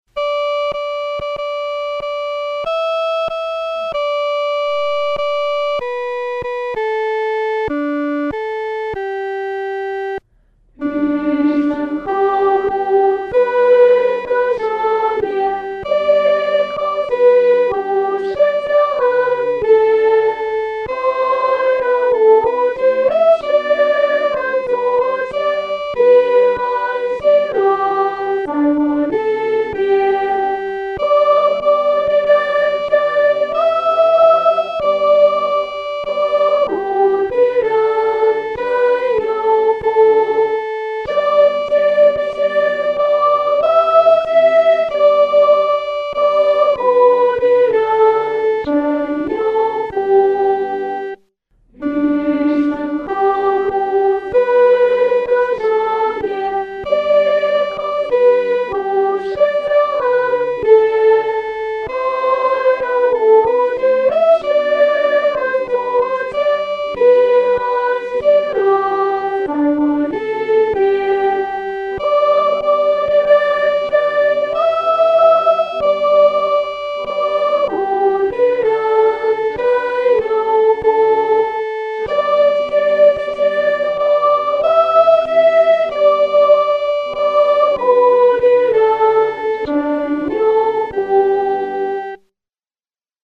合唱
女高
这首诗歌可用进行曲般的速度弹唱，不宜太拖沓。